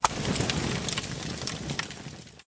描述：Fire Start 使用Earthworks QTC30和LiquidPre
声道立体声